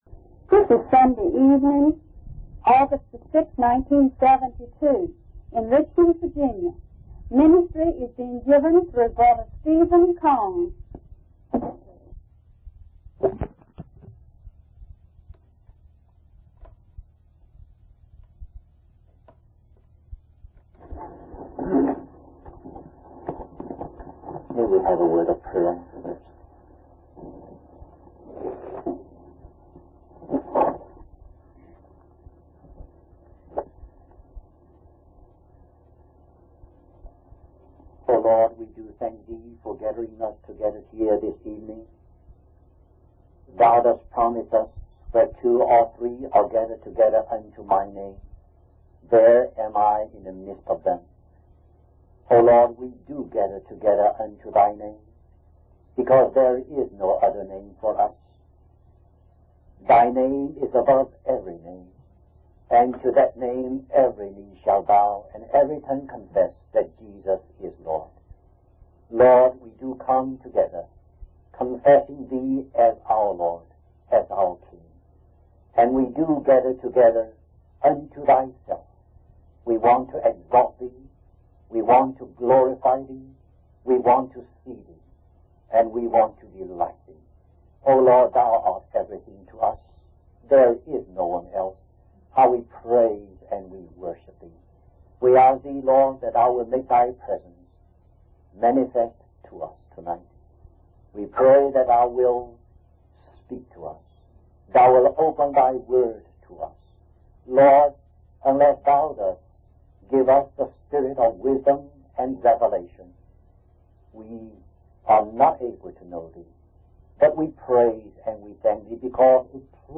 In this sermon, the speaker emphasizes that there is only one gospel, the gospel of Jesus Christ.